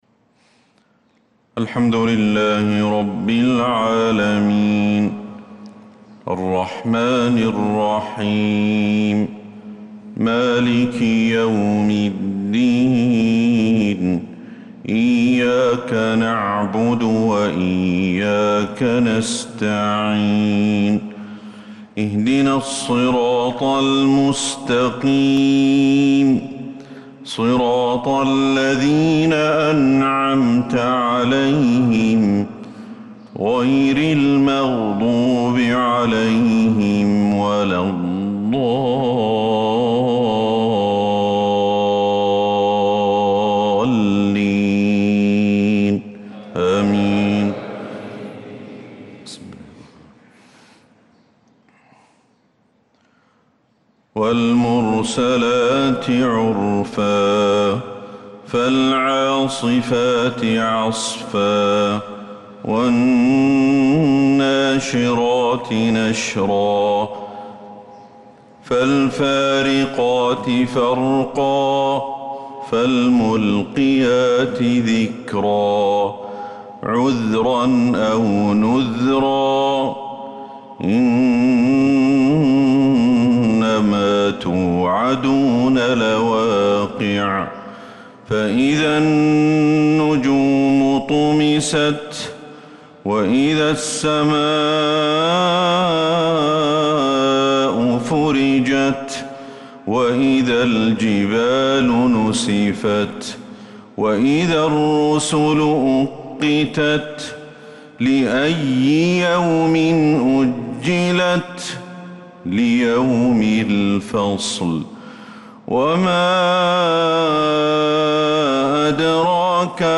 صلاة الفجر للقارئ أحمد الحذيفي 4 ذو القعدة 1445 هـ